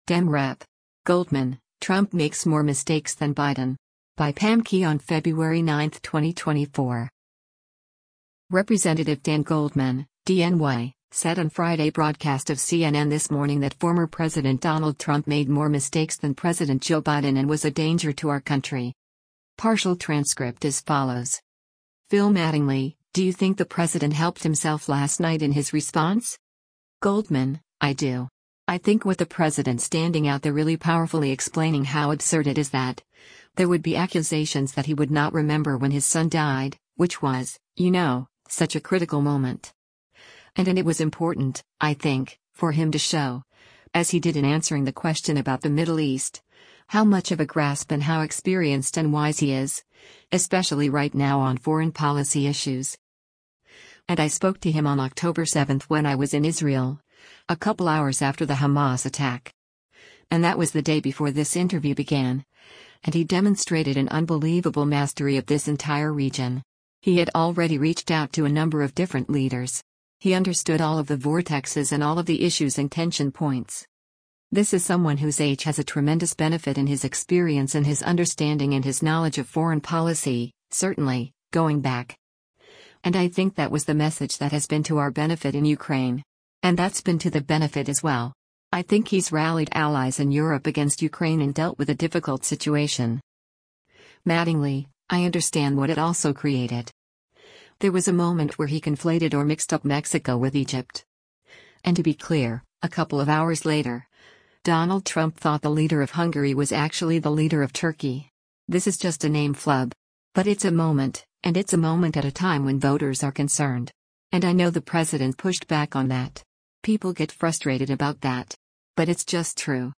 Representative Dan Goldman (D-NY) said on Friday broadcast of “CNN This Morning” that former President Donald Trump made “more mistakes” than President Joe Biden and was “a danger to our country.”